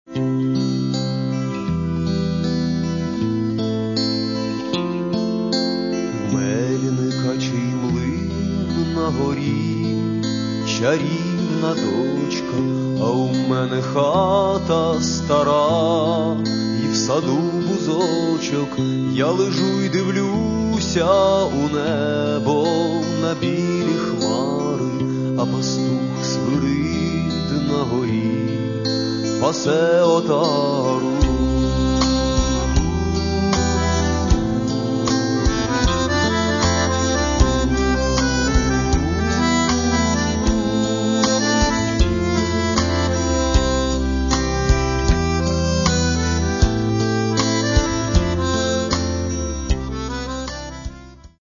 Каталог -> Рок и альтернатива -> Фольк рок